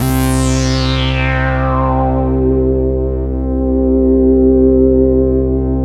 SYN JD-8003R.wav